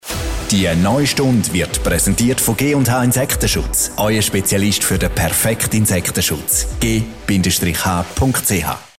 Stundensponsoring
Als Sponsor einer ganzen Stunde Radioprogramm wird Ihre Marke zur vollen Stunde prominent genannt.